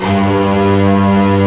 FLSTRINGS1G2.wav